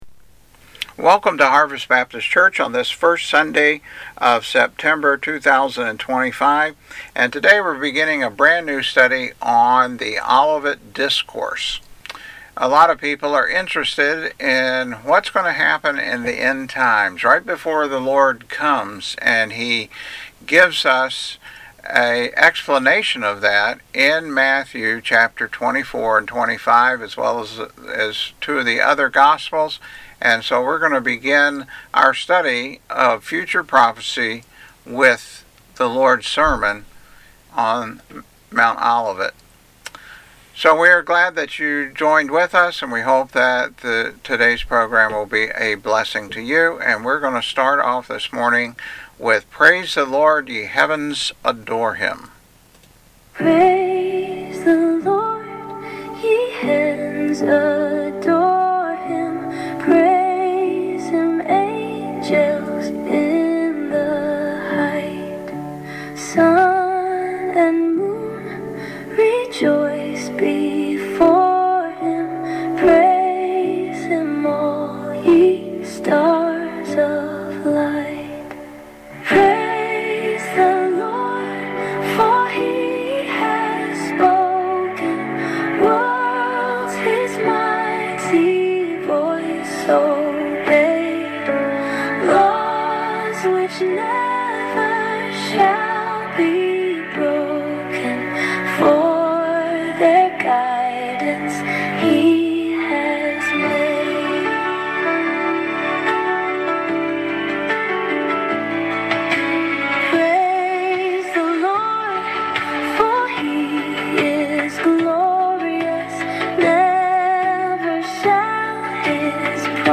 Sermons | Harvest Baptist Church